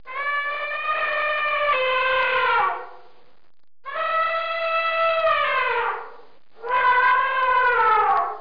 جلوه های صوتی
دانلود صدای فیل برای کودکان از ساعد نیوز با لینک مستقیم و کیفیت بالا
برچسب: دانلود آهنگ های افکت صوتی انسان و موجودات زنده